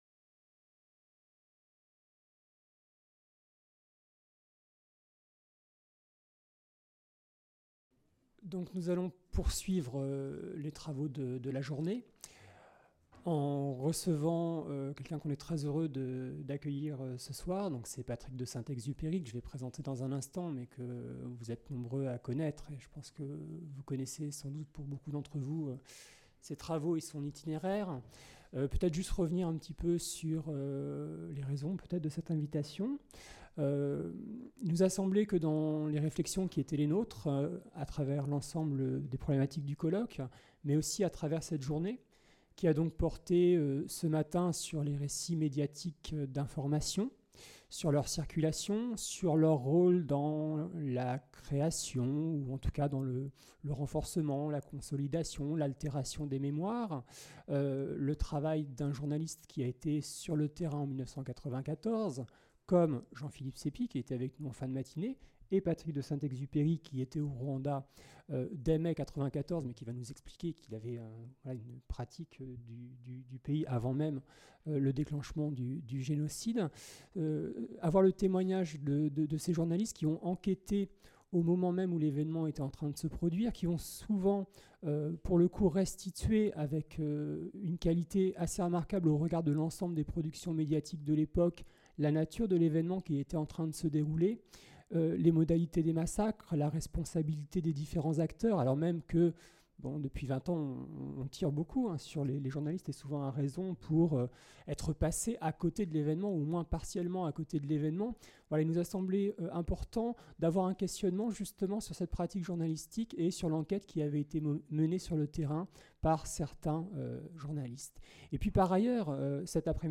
10 - Grand entretien avec Patrick de Saint-Exupéry | Canal U